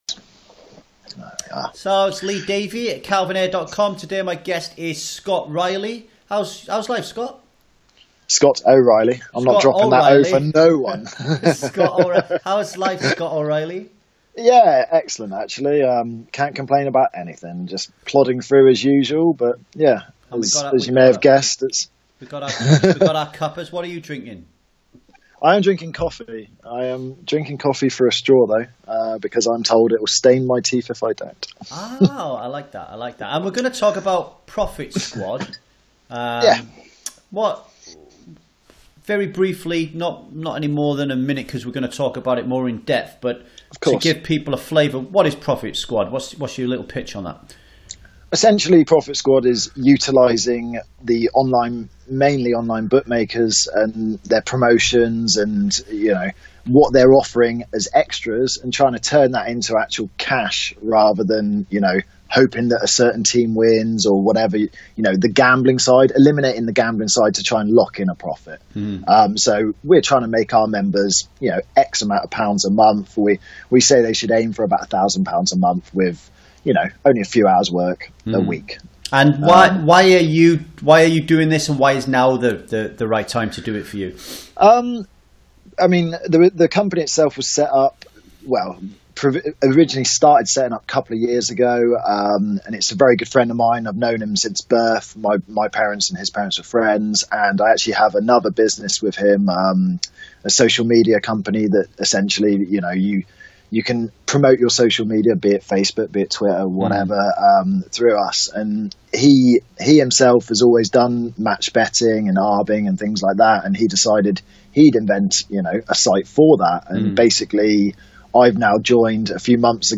You are about to read an abridged version of an audio interview that was 30-minutes in length.